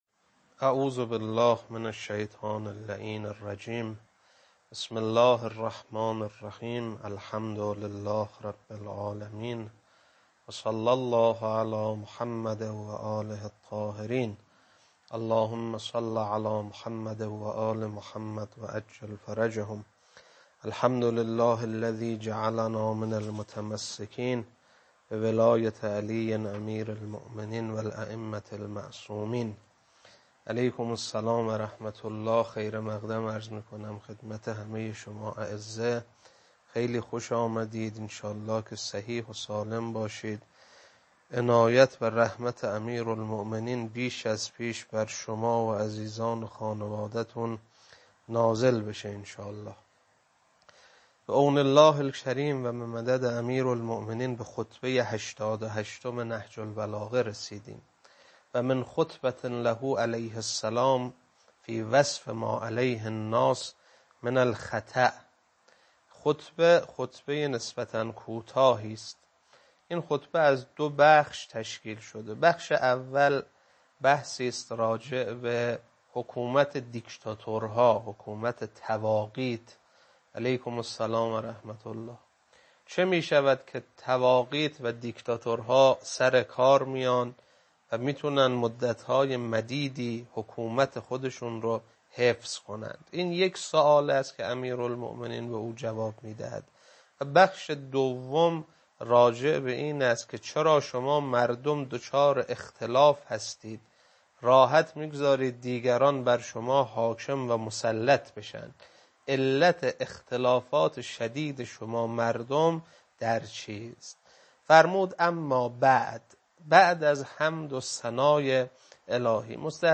خطبه 88.mp3